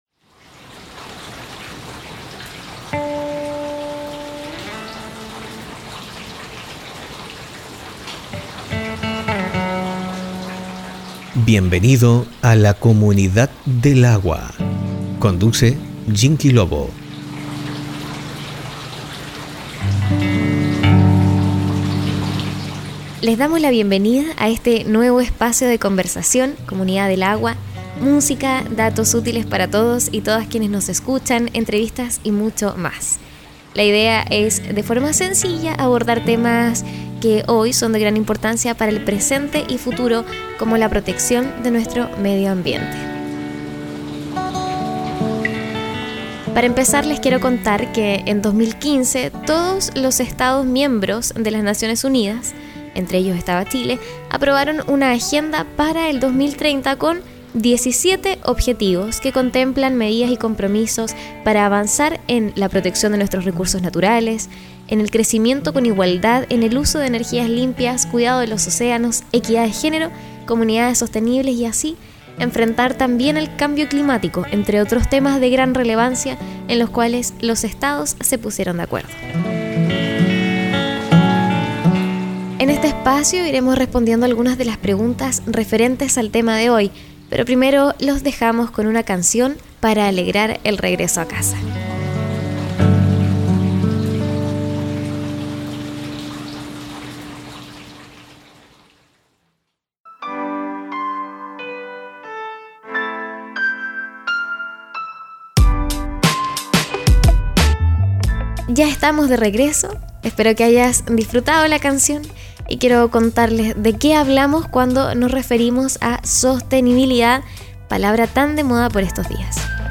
una entrevista
"Comunidad del Agua" se trasmite en Radio Mega 90.3 Fm, Osorno, los días viernes desde las 18.30 horas.